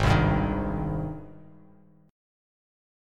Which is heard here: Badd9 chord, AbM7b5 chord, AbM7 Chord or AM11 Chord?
AbM7 Chord